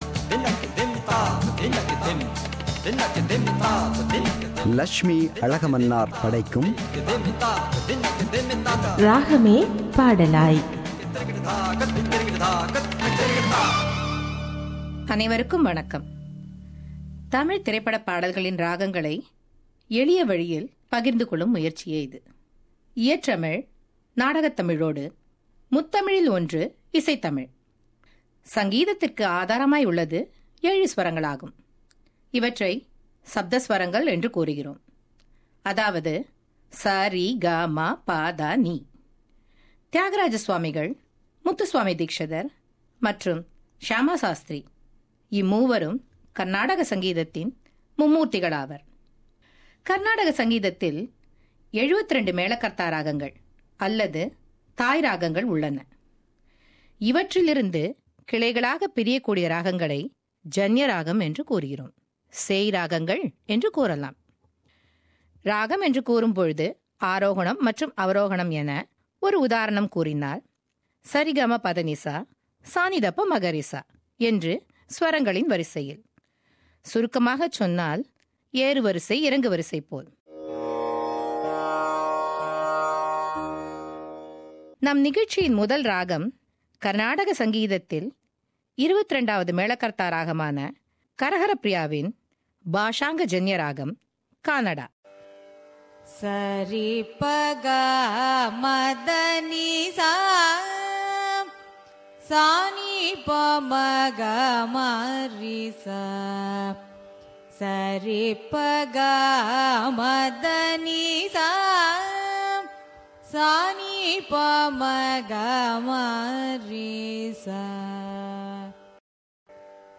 கானடா ராகம் எப்படி பாடல் வடிவம் பெறுகிறது என்பதை கர்நாடக இசை மற்றும் திரைப்பட இசை ஒலிக்கீற்றுகளைக் கலந்து இந்நிகழ்ச்சியைப் படைக்கிறார்